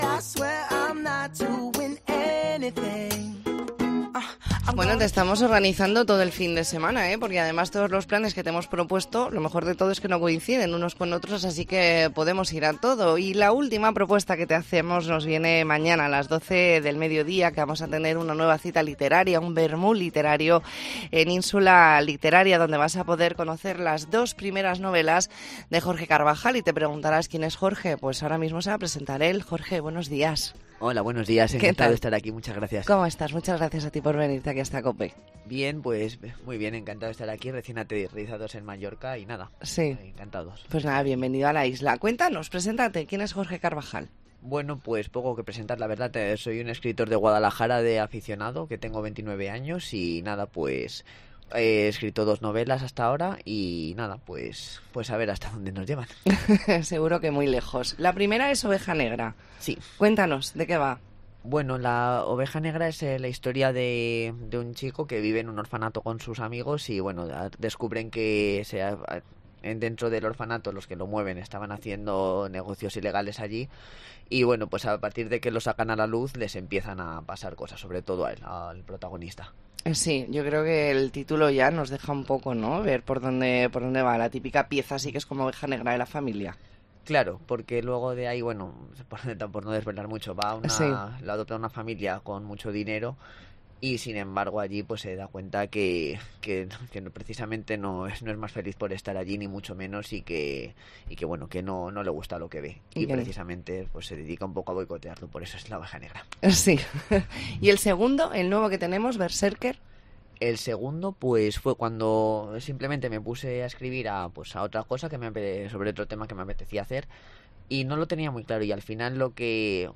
ntrevista en La Mañana en COPE Más Mallorca, viernes 3 de noviembre de 2023.